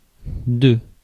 Ääntäminen
Ääntäminen France (Paris): IPA: [dø] Tuntematon aksentti: IPA: /də/ IPA: /dam/ Haettu sana löytyi näillä lähdekielillä: ranska Käännös Ääninäyte 1. por 2. sobre {m} 3. de {f} 4. a {f} 5. acerca de Suku: f .